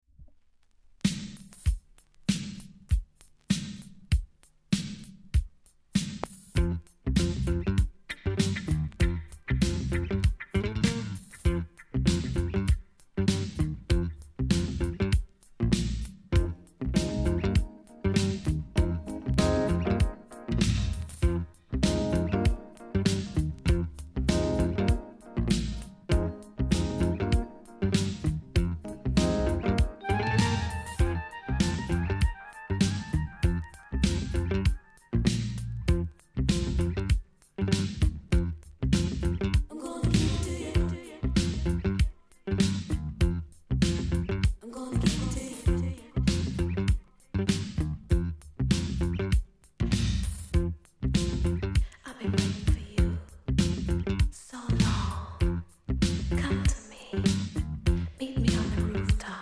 ※小さなチリノイズが少しあります。
DISCO REGGAE BOOGIE!!